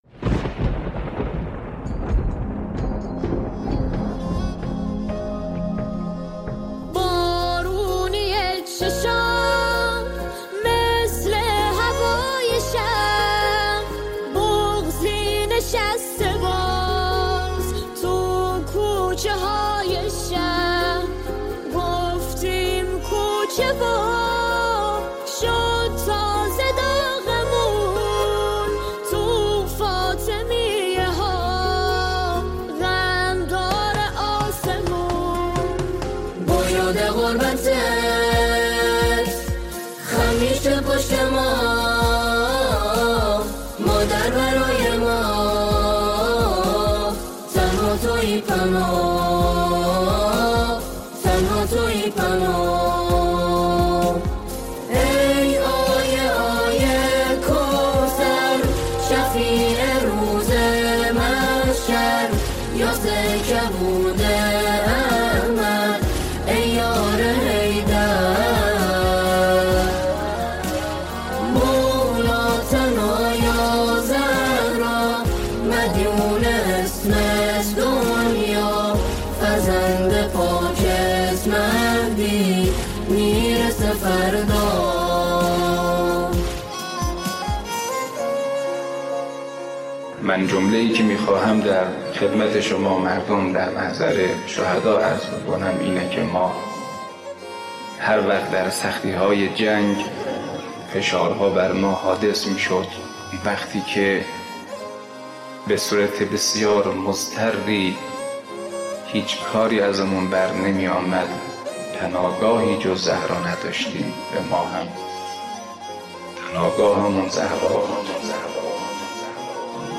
سرودهای حضرت فاطمه زهرا سلام الله علیها